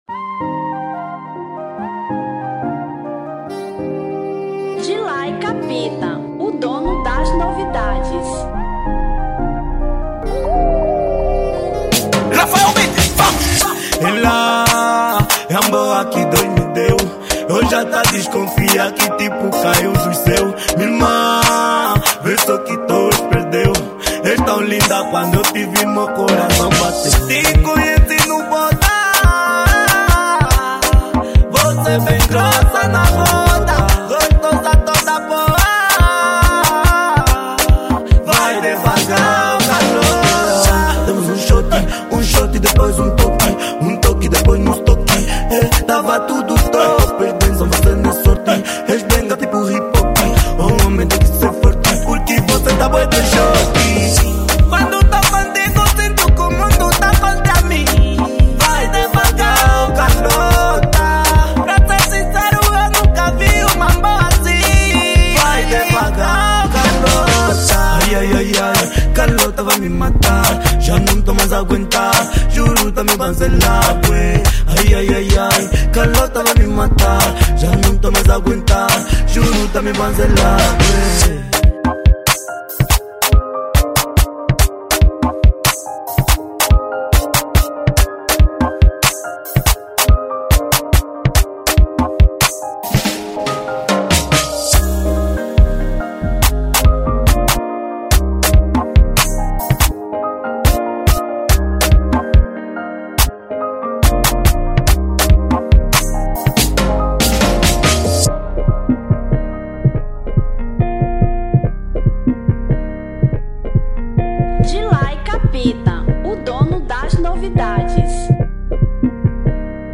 Kuduro